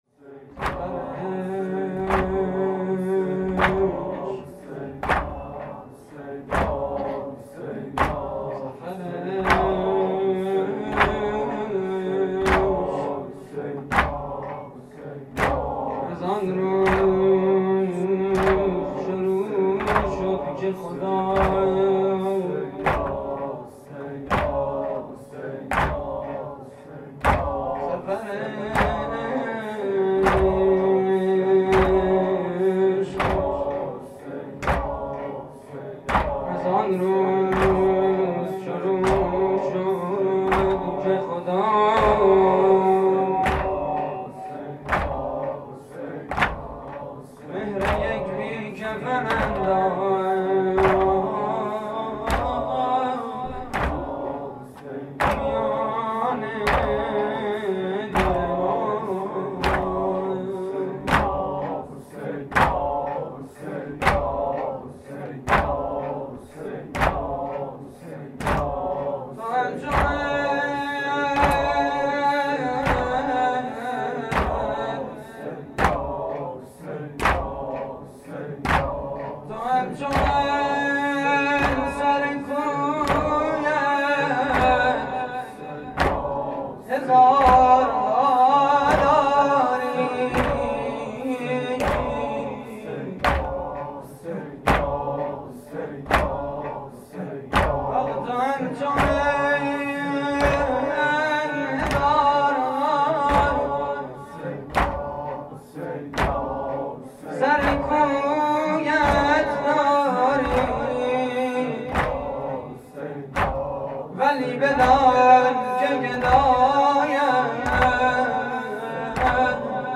مراسم عزاداری شب ششم محرم 1432